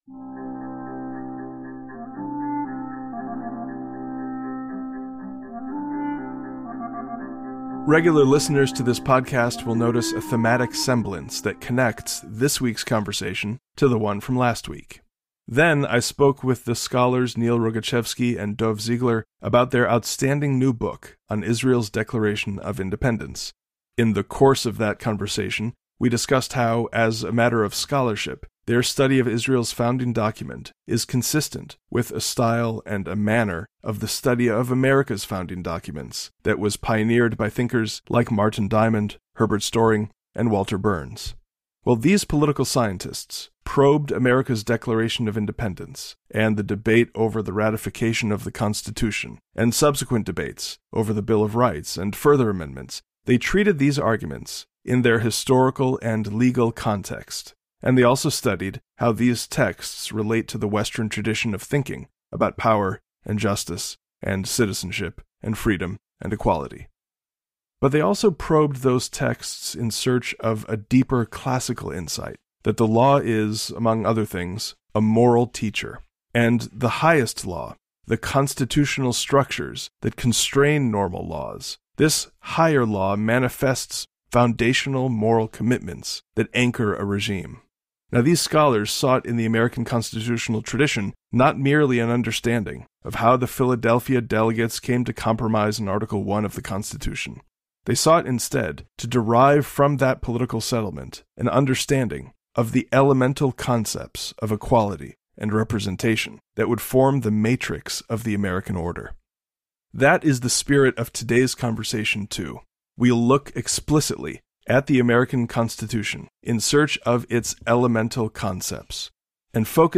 Join us for a discussion with Yuval Levin on the Israeli Judicial Crisis and whether American Constitutionalism could serve as a viable solution!